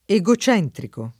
vai all'elenco alfabetico delle voci ingrandisci il carattere 100% rimpicciolisci il carattere stampa invia tramite posta elettronica codividi su Facebook egocentrico [ e g o ©$ ntriko ] agg. e s. m.; pl. m. -ci — cfr. centro